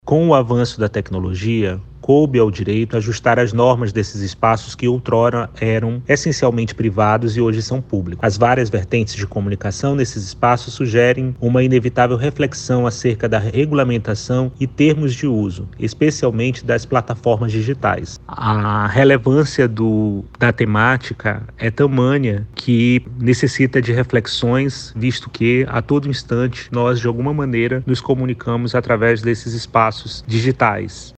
O juiz, Aldrin Henrique, explica a importância da discussão dos temas.